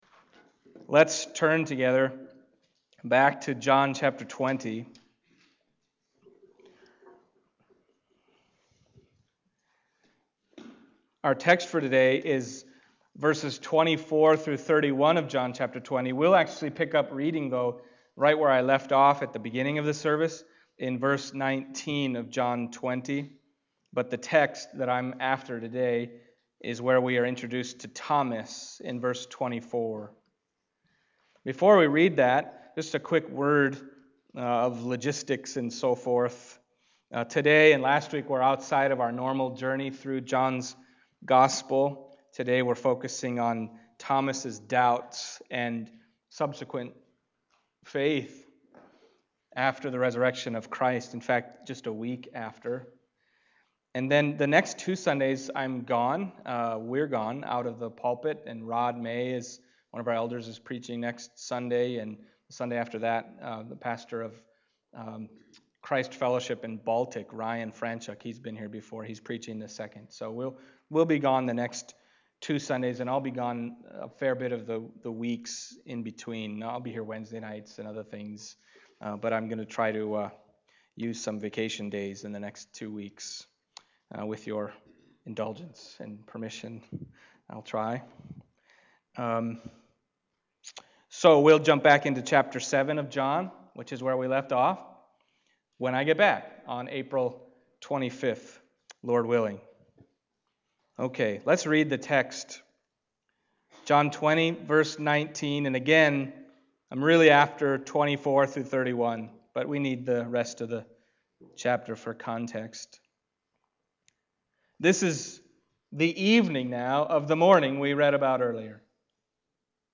John Passage: John 20:24-31 Service Type: Sunday Morning John 20:24-31 « I Am the Resurrection and the Life Christ